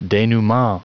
Prononciation du mot denouement en anglais (fichier audio)
denouement.wav